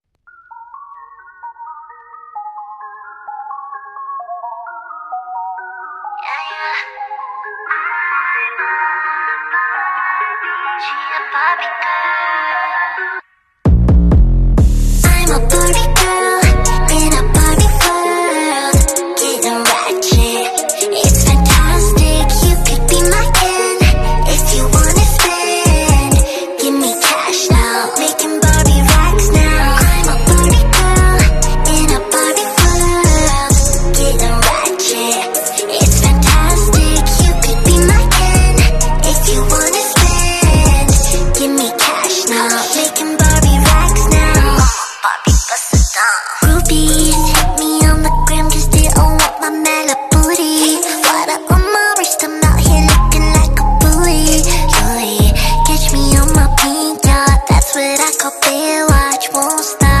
phonics song remix